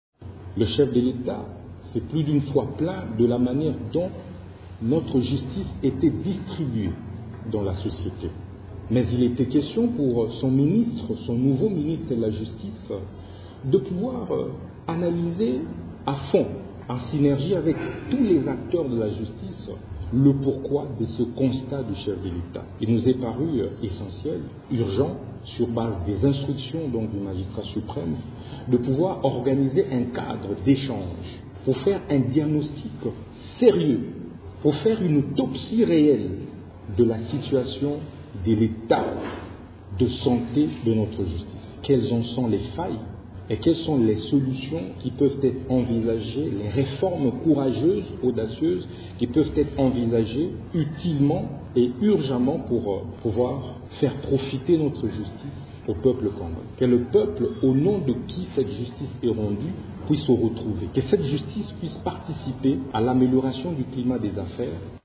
Au cours d'une conférence de presse qu'il a animée ce lundi à son cabinet, Constant Mutamba fait savoir qu'il faudra attendre de ces assises une nouvelle politique nationale de la justice.